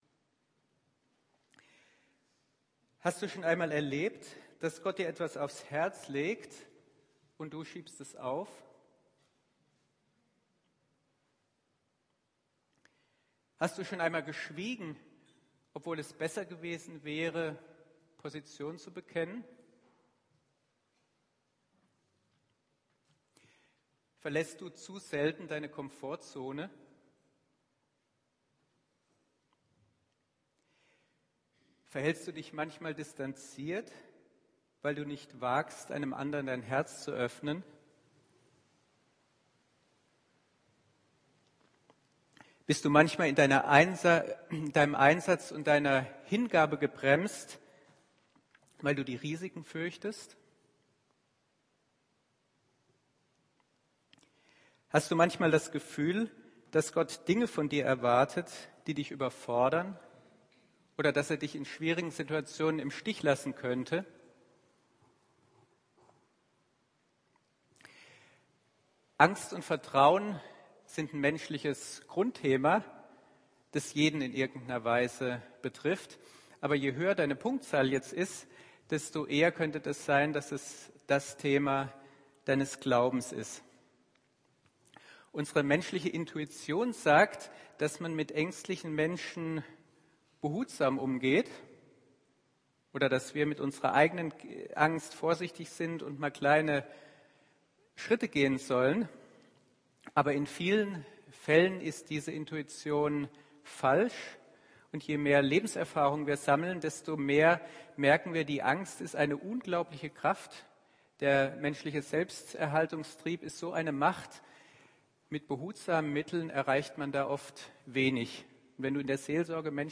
Predigt von 04.08.2019 über das Thema „Wie wir Angst überwinden“ zu Matthäus 25,14-30.